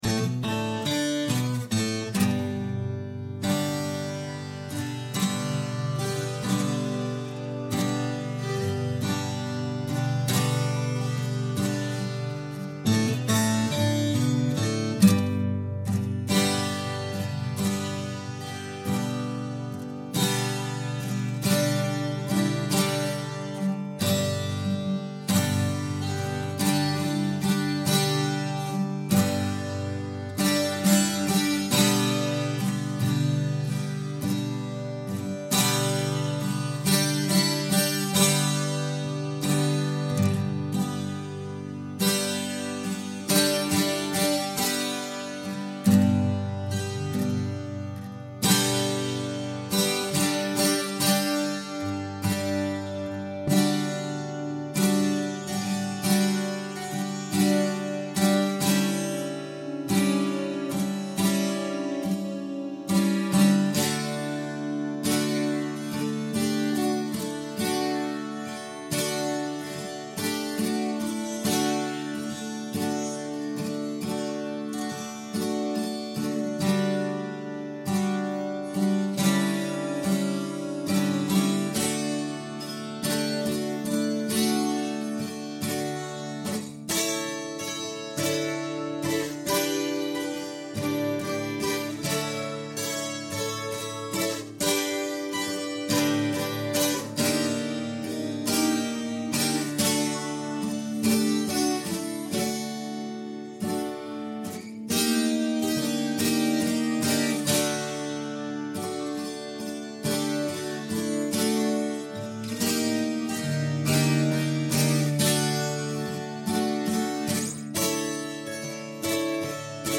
А вот гитара черновая: